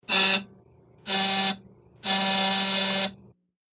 Intercoms|Buzzers | Sneak On The Lot